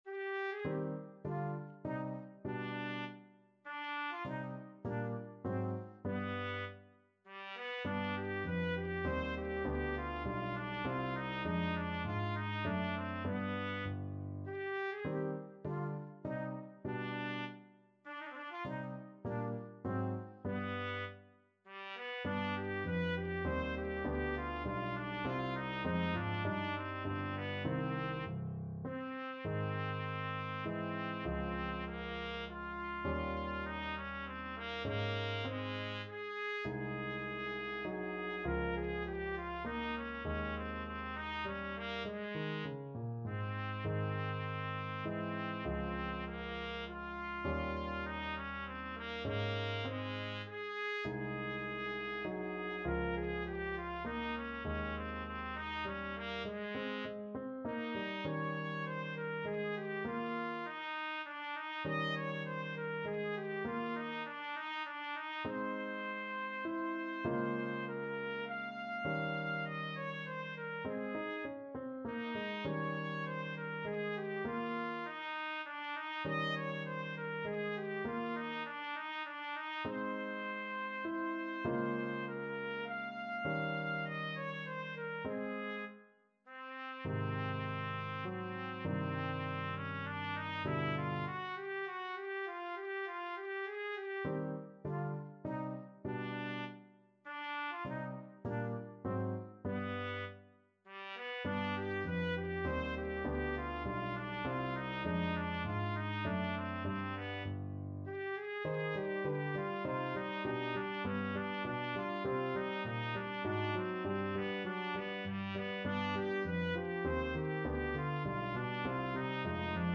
Trumpet version
3/4 (View more 3/4 Music)
Allegretto
Classical (View more Classical Trumpet Music)